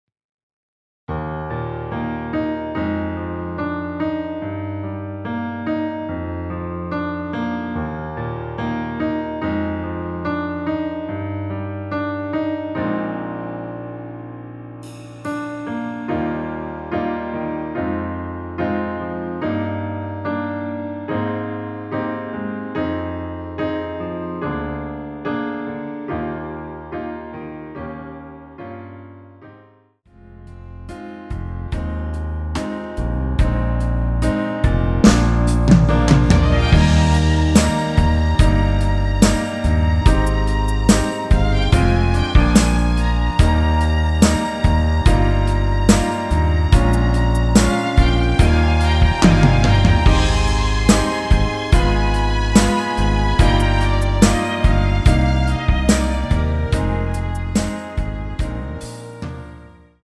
원키(1절앞+후렴)으로 진행되는 MR입니다.
Eb
앞부분30초, 뒷부분30초씩 편집해서 올려 드리고 있습니다.
중간에 음이 끈어지고 다시 나오는 이유는